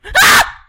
又是一阵尖叫声
描述：警告：大声 另一个害怕的尖叫声
Tag: 恐慌 尖叫 大喊